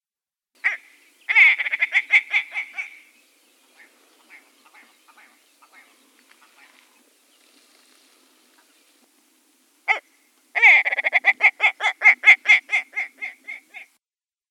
На этой странице собраны звуки, издаваемые куропатками различных видов.
Звук приманивания самца куропатки